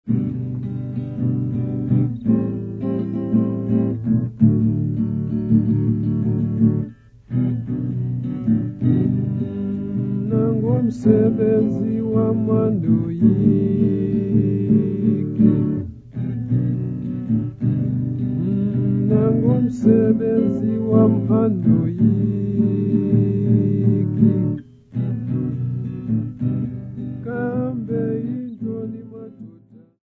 Folk music -- South Africa
Guitar
field recordings
Topical Xhosa urban song with guitar accompaniment
Cassette tape